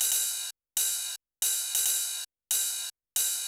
OP HH     -R.wav